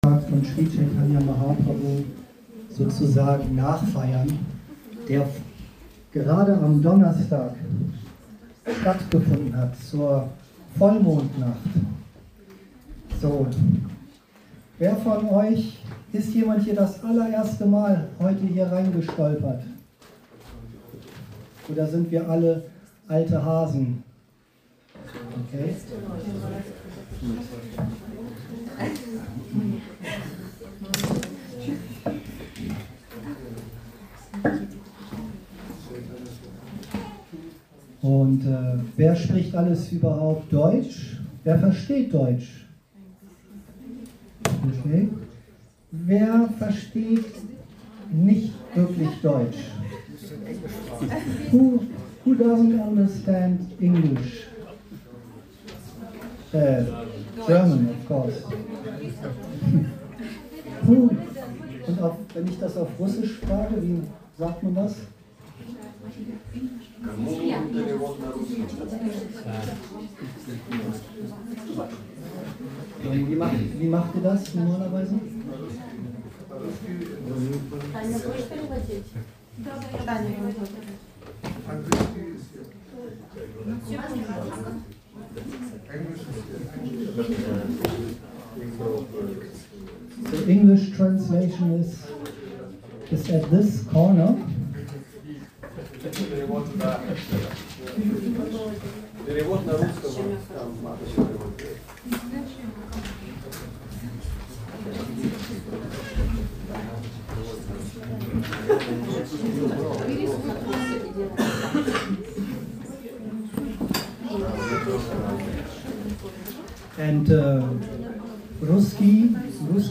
Vorträge im Bhakti Yoga Zentrum Hamburg Podcast